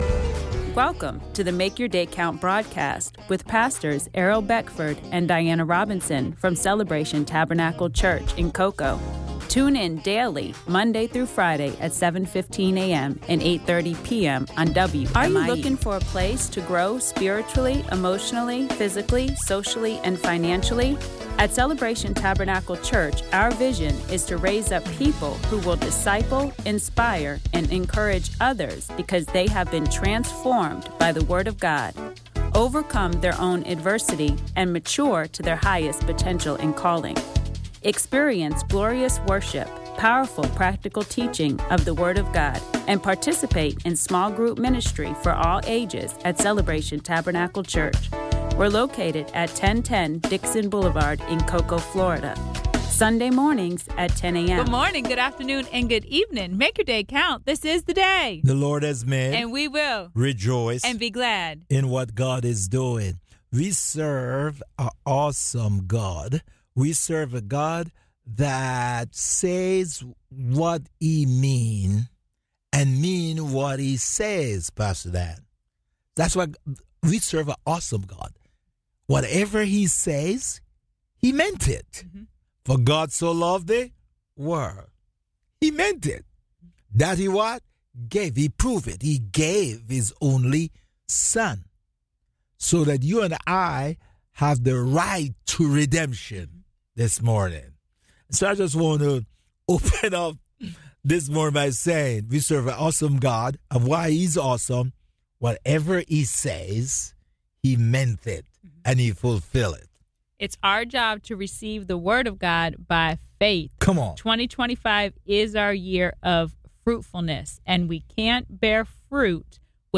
Sermon: Sermon on the Mount Part 3 Matthew Ch. 5